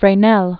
(frā-nĕl), Augustin Jean 1788-1827.